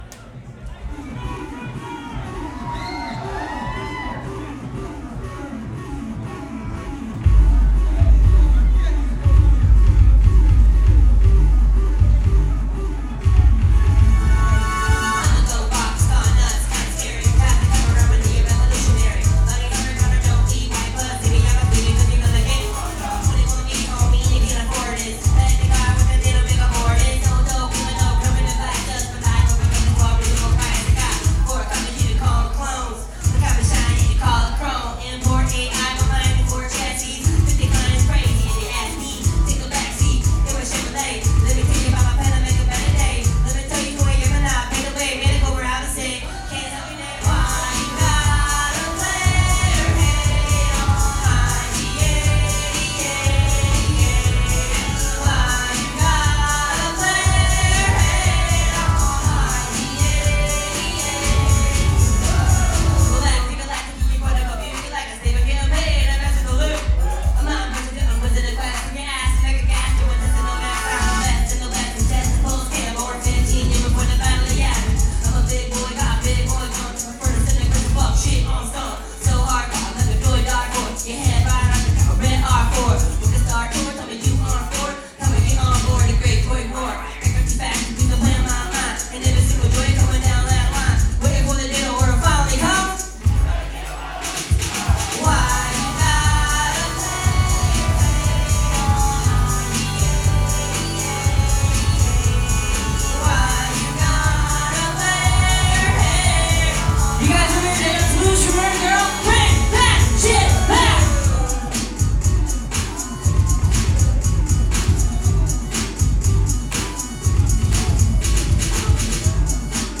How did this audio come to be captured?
atlanta, ga at the masquerade on november 12th 2009 audience microphone recording